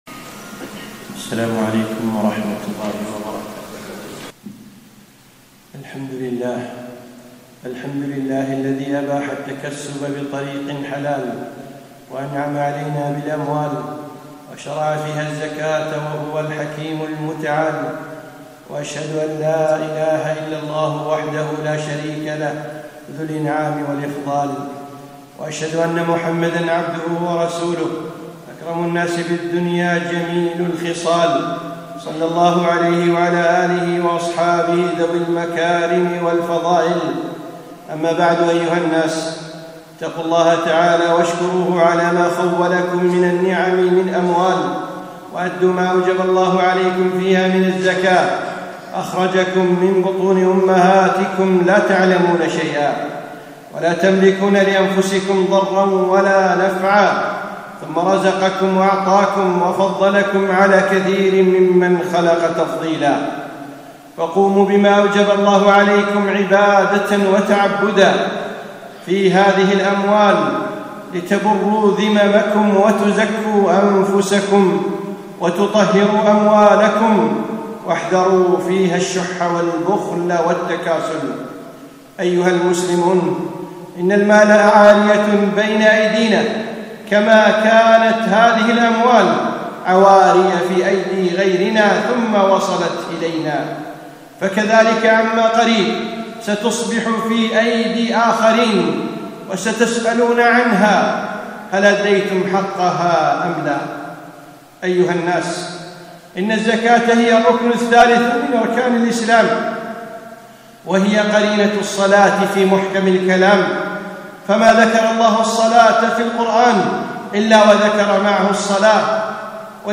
خطبة - زكاة المال